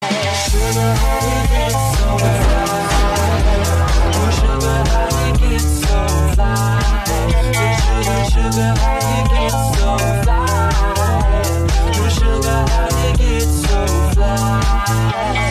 • Качество: 320, Stereo
мужской вокал
dance
спокойные
club